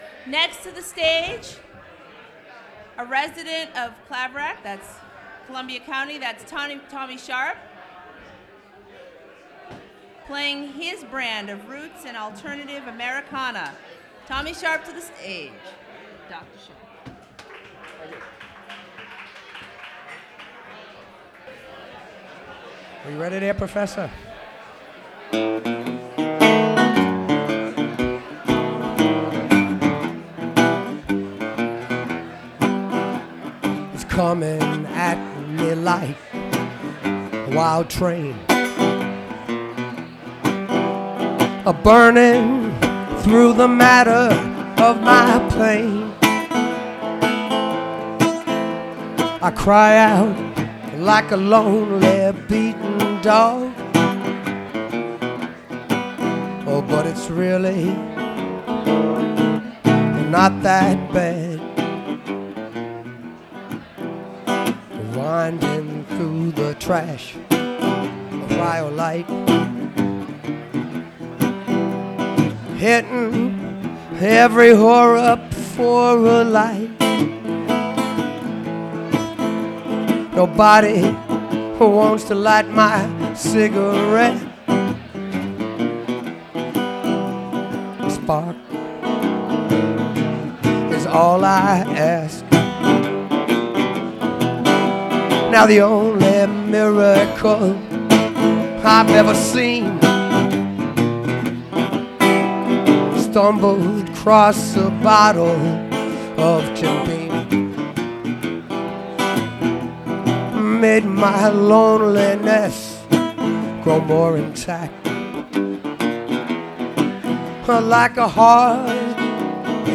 Recorded from WGXC 90.7-FM webstream.